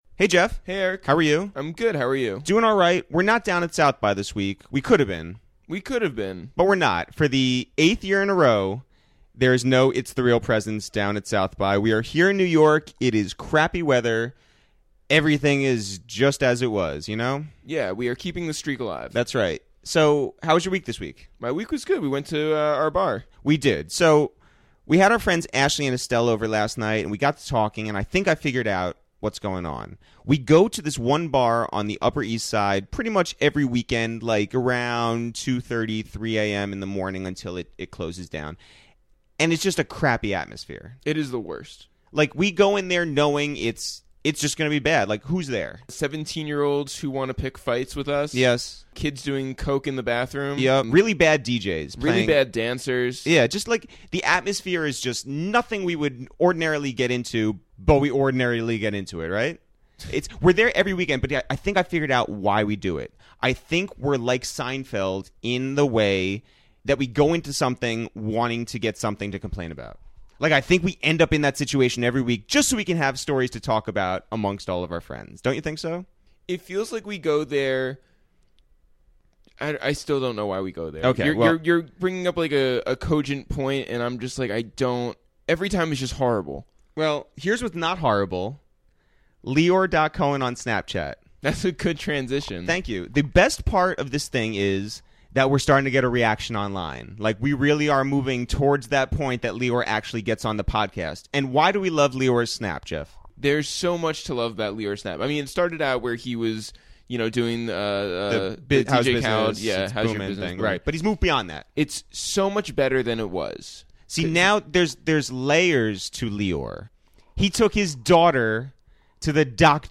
This week on A Waste of Time with ItsTheReal, we welcomed acclaimed producer Harry Fraud to the Upper West Side, and as a bonus, we got a visit from our friend Smoke DZA, who just dropped his new project with Harry Fraud, He Has Risen! Harry discussed his infamous producer tag, his jobs as a teenage stage tech and weed delivery person, and his hilarious first encounter with French Montana.